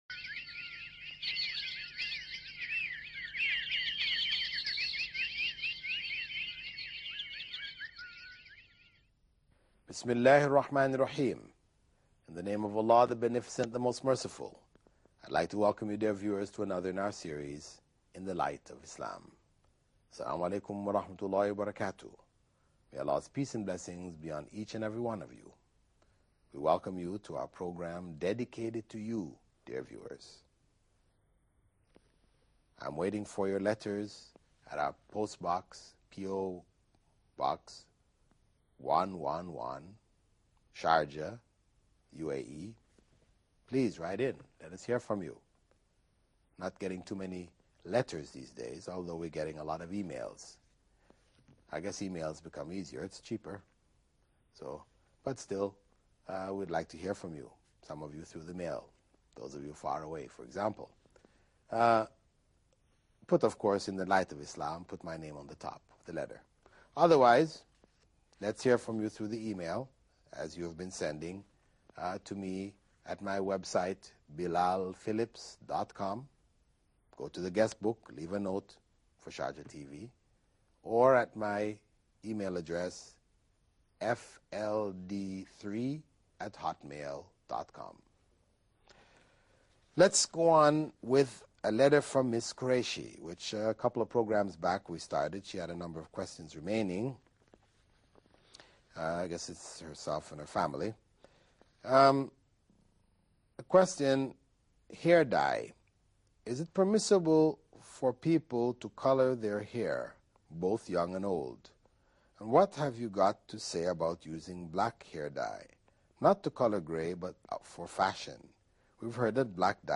In the Light of Islam – Questions and Answers